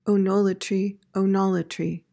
PRONUNCIATION:
(oh-NOL/NAHL-uh-tree)